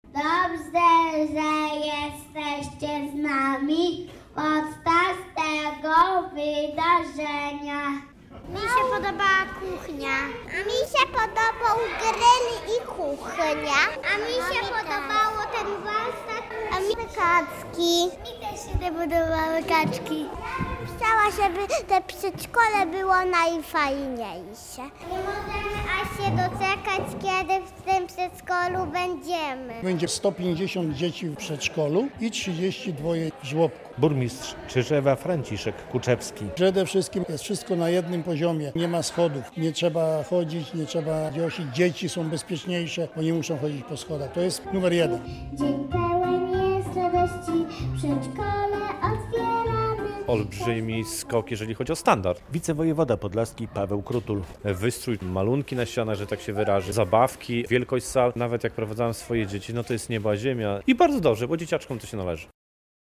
W Czyżewie oficjalnie otwarto nowy budynek przedszkola i żłobka - relacja